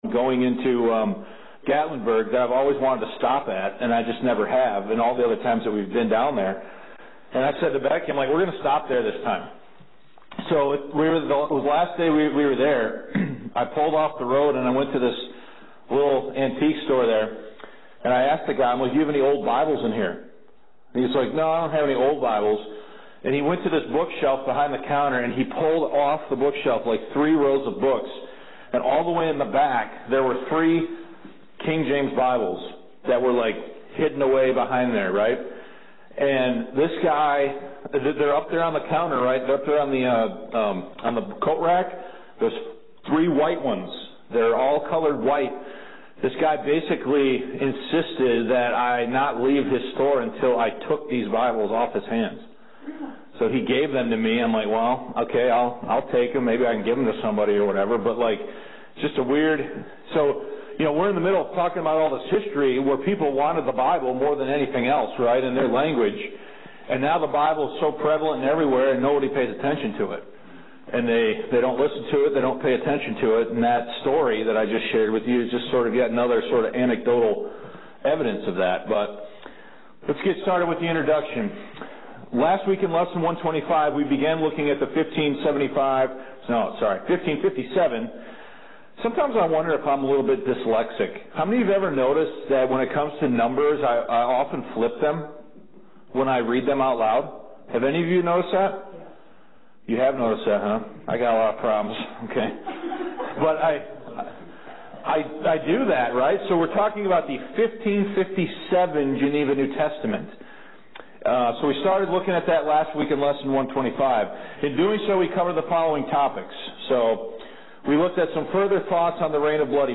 Lesson 126 The 1557 Geneva New Testament: Assessing the Text